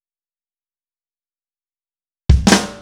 Indie Pop Beat Intro 05.wav